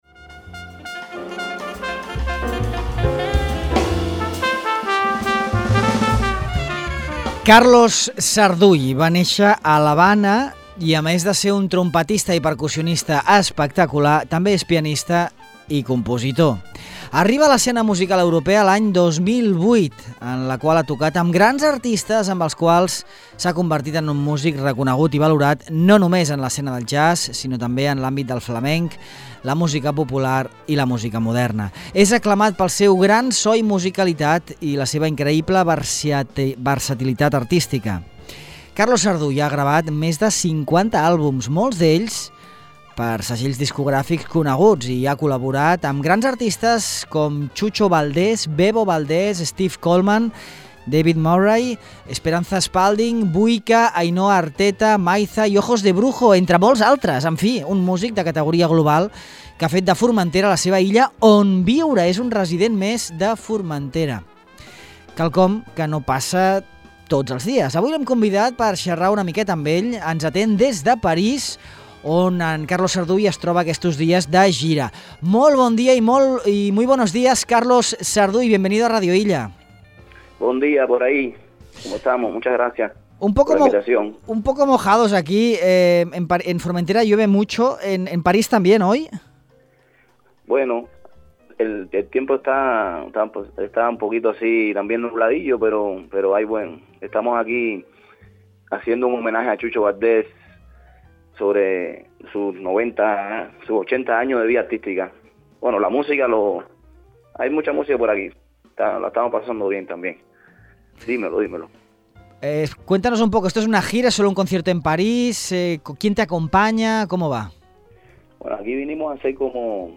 Entrevistem
Fa uns dies ens va atendre des de París, on és de gira en aquests moments.